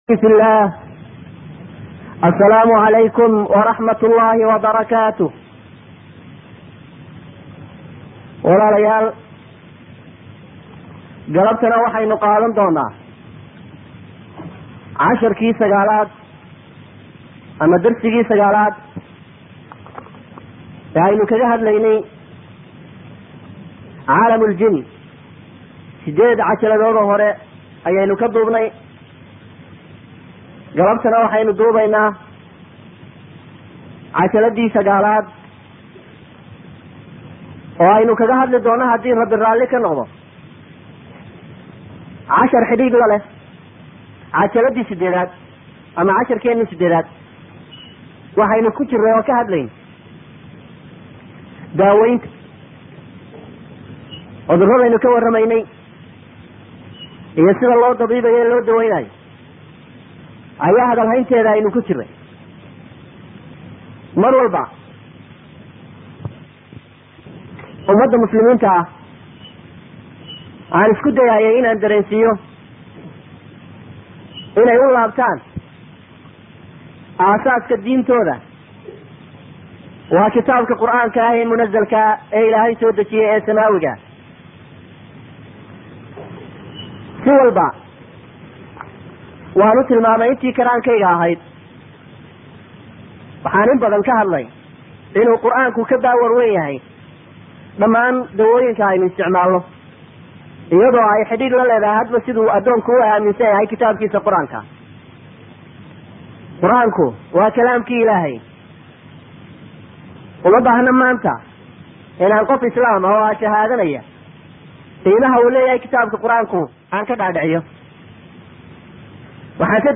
08 Casharka Qur'aanka lagu akhriyo Sixirka